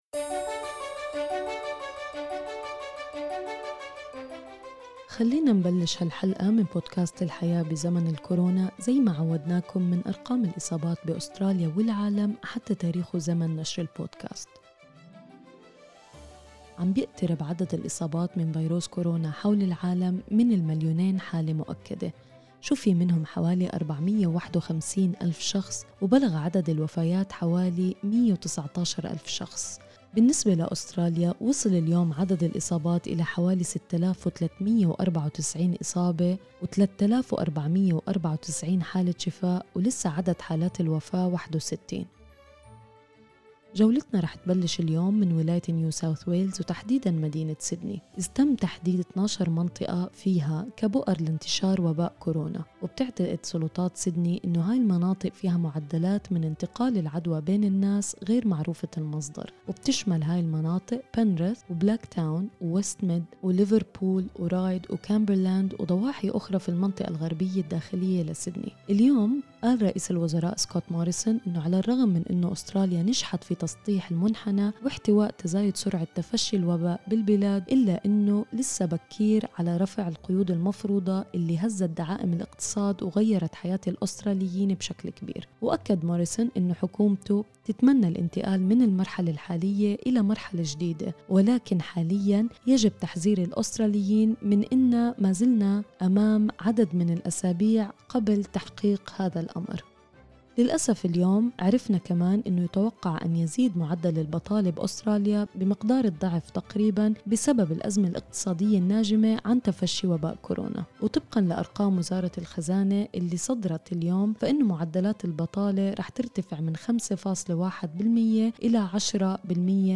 أخبار الكورونا اليوم 14/4/2020